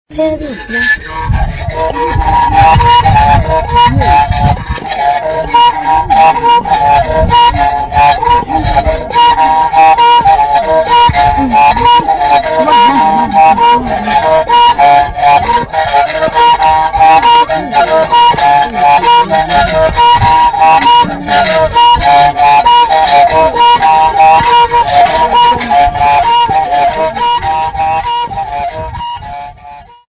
Group of Sikhwankqeni locals
Folk music
Africa South Africa Sikhwankqeni sa
field recordings
Traditional song with Umrhube accompaniment.
7.5 inch reel